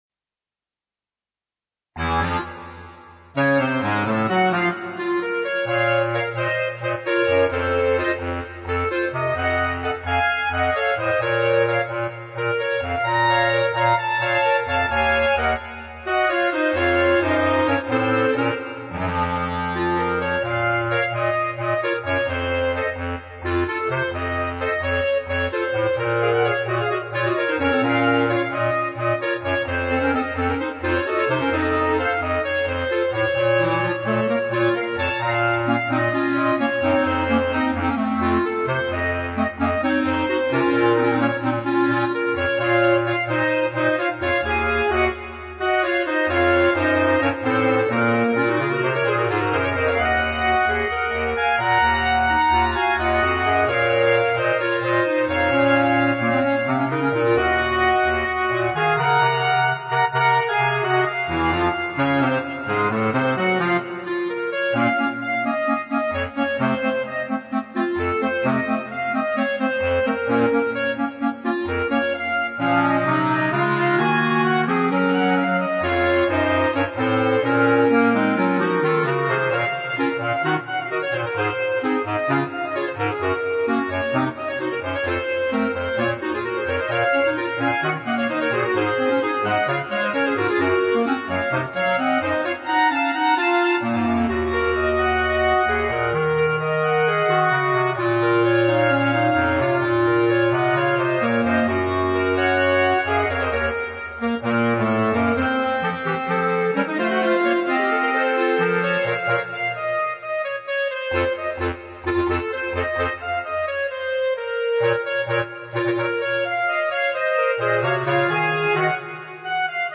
B♭ Clarinet 1 B♭ Clarinet 2 B♭ Clarinet 3 Bass Clarinet
单簧管四重奏